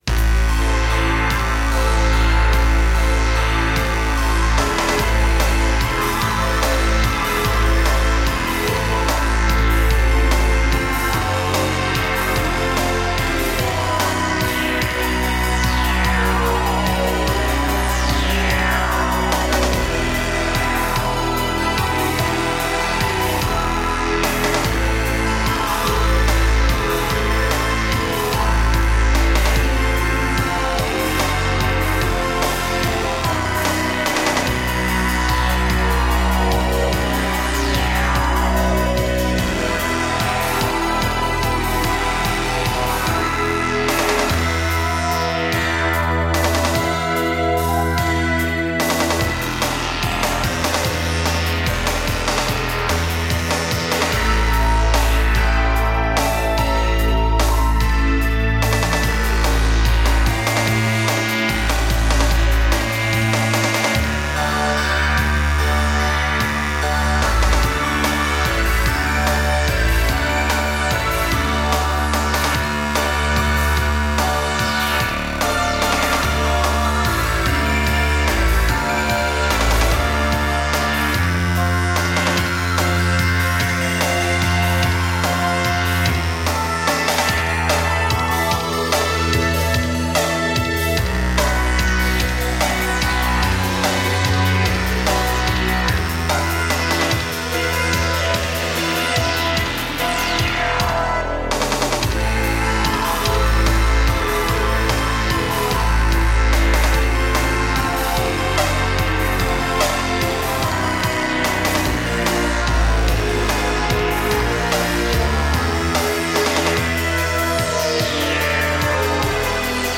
Recorded AAD - Analogue Analogue Digital